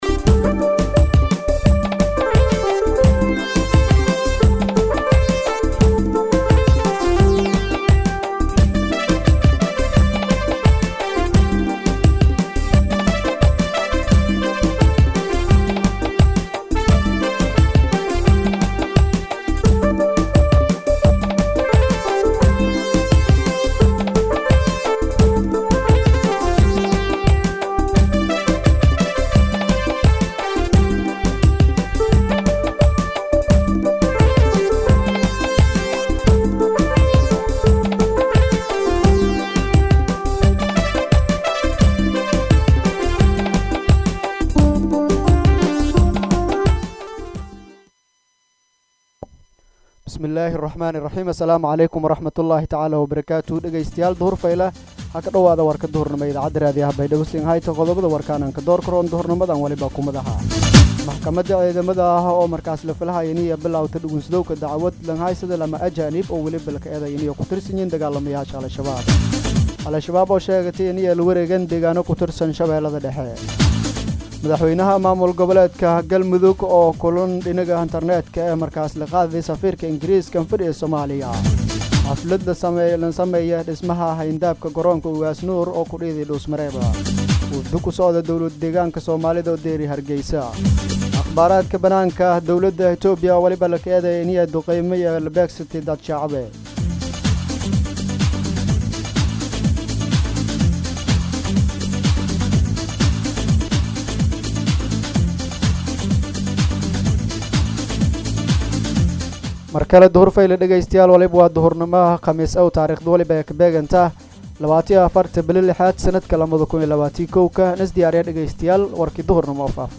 warka-duxur.mp3